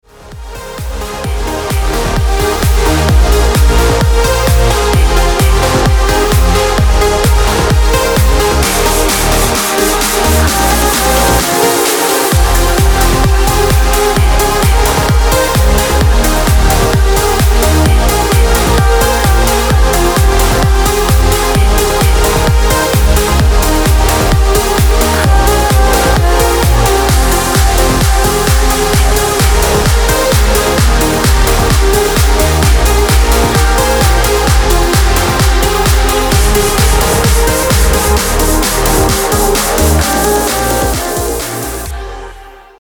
• Качество: 320, Stereo
мощные
progressive trance
Uplifting trance
vocal trance